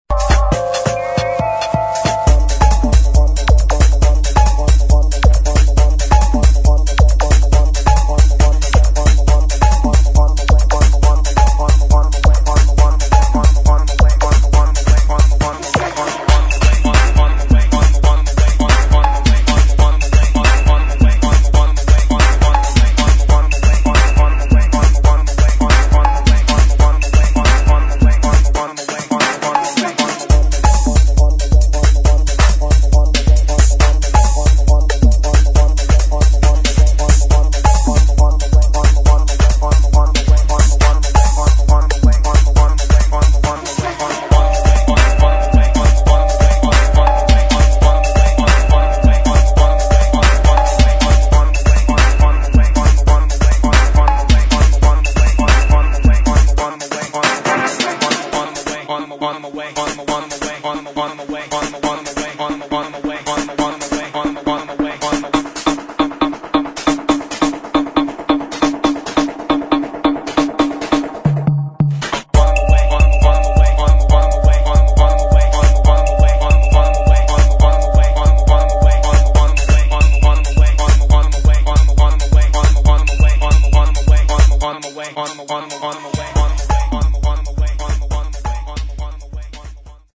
[ HOUSE | BASS ]